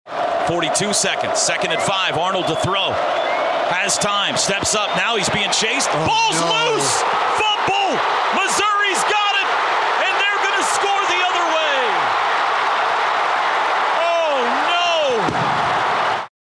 OU - PBP Mizzou TD 11-11.mp3